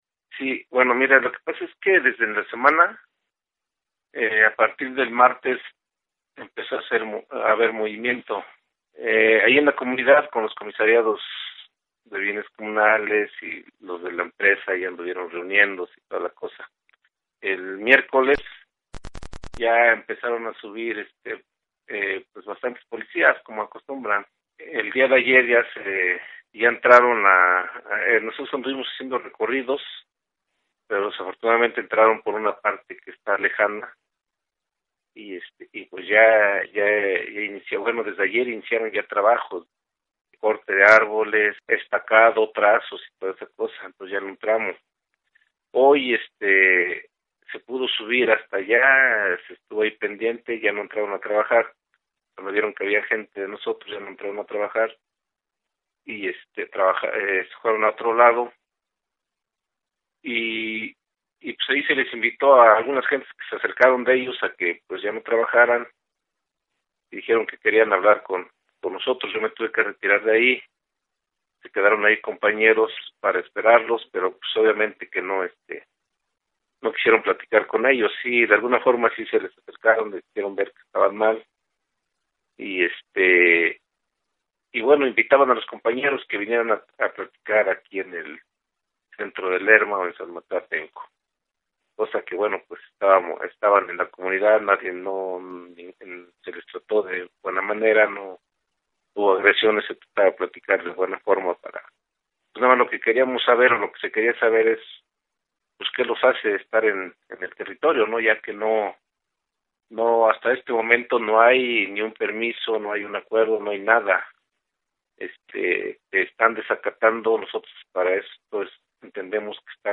Entrevista: En peligro el Bosque Otomí Mexica
Bosque_Otomi_Entrevista.mp3